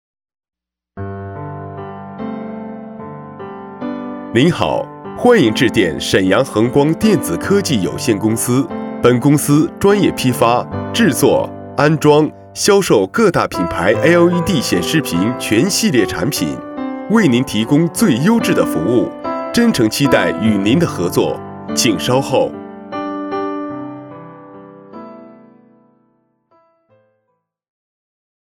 C男165号
【彩铃】厚重磁性彩铃混音
【彩铃】厚重磁性彩铃混音.mp3